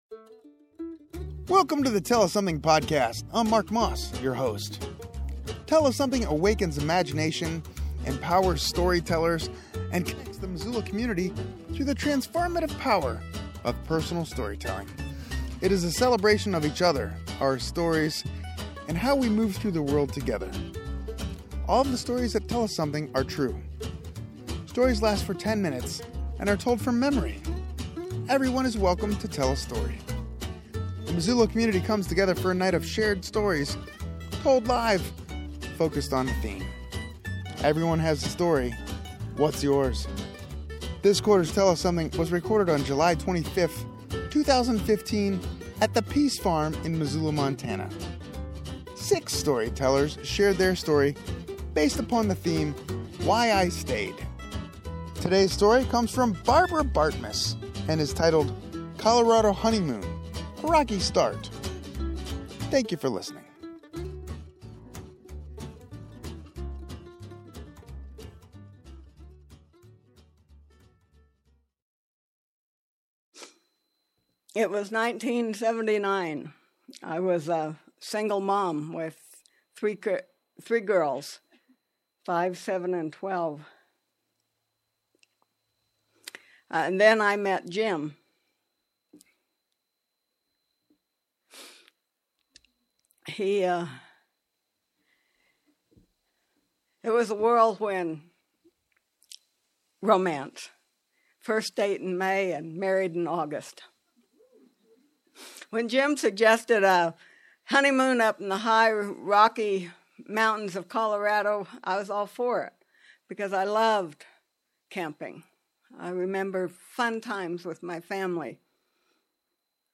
This edition of Tell Us Something was recorded on July 25, 2015 at the PEAS Farm in Missoula, MT.